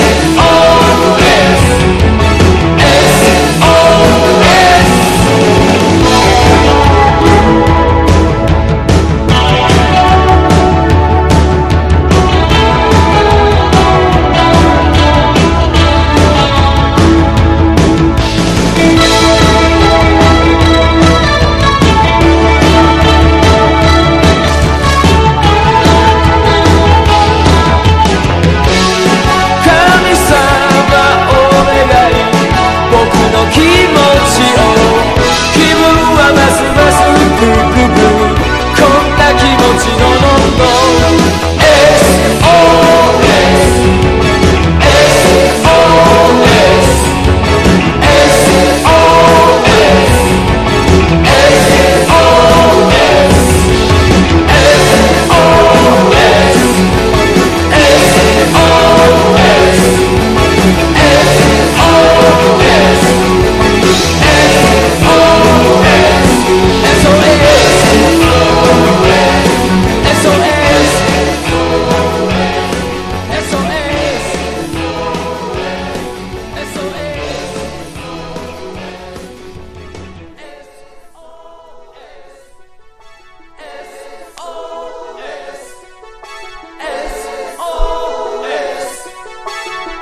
シンセを取り入れよりポップなサウンドへ舵を切ったバンドの模索を感じる1枚です。
# 60-80’S ROCK